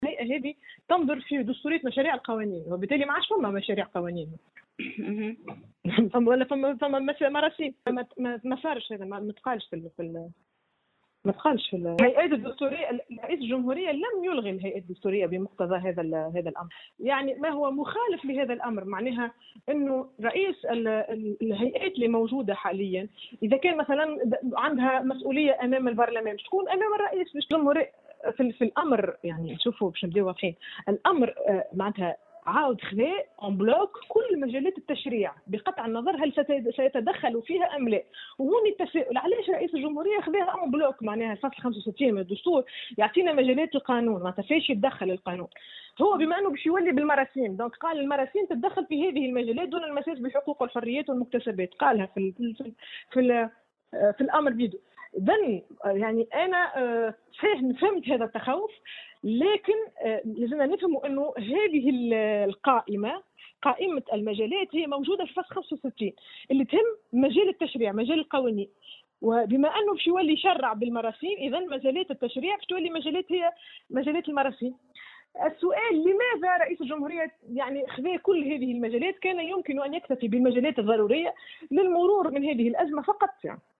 Dans une déclaration accordée à Tunisie numérique